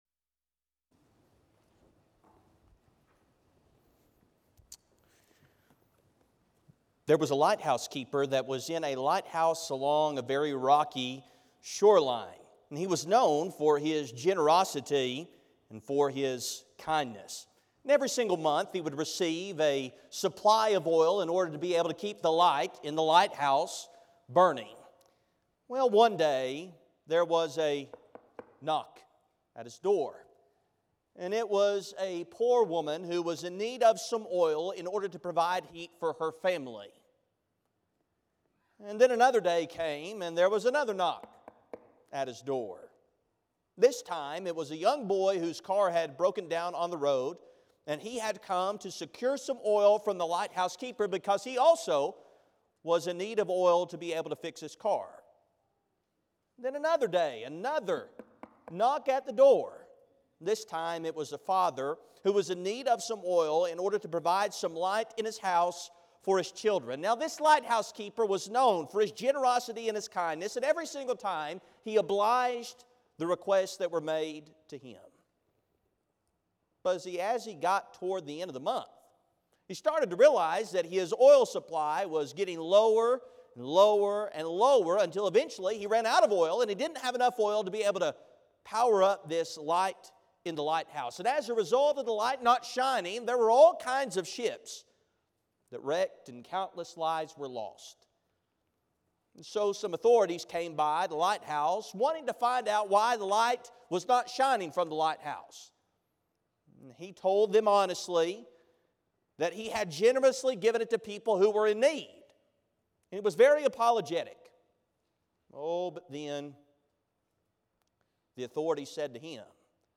The sermon is from our live stream on 11/10/24